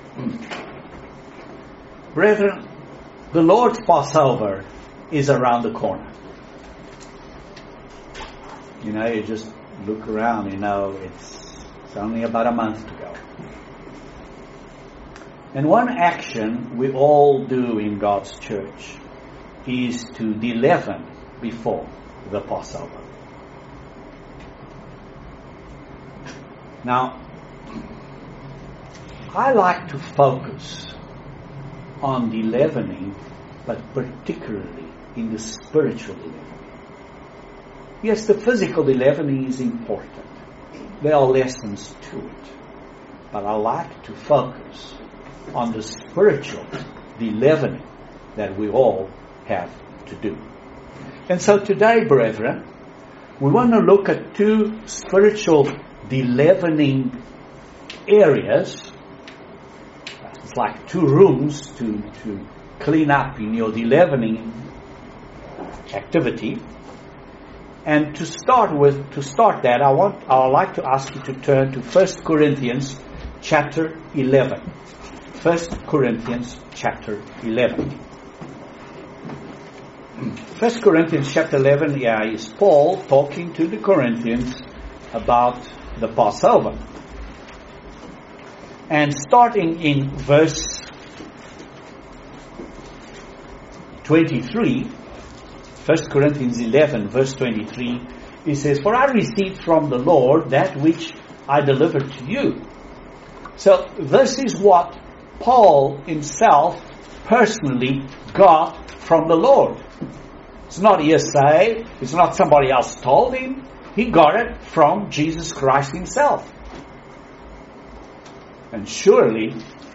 Great Sermon on de-leavening and self examination.